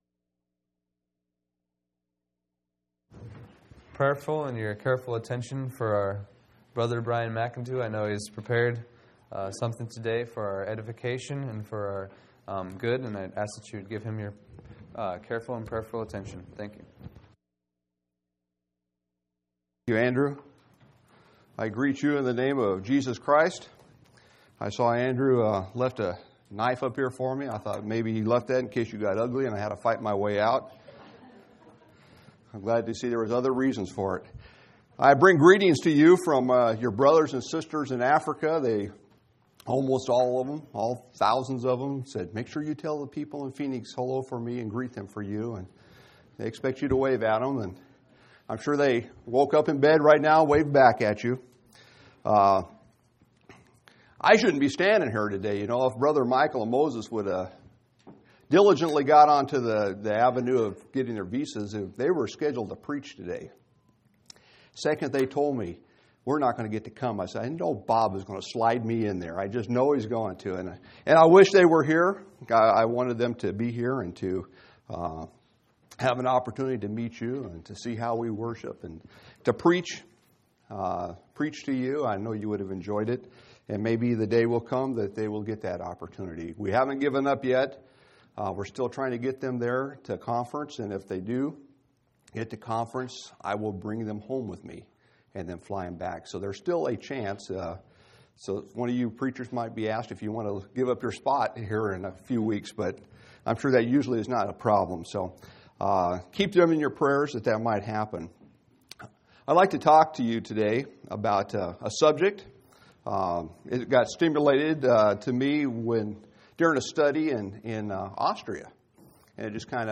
3/20/2005 Location: Phoenix Local Event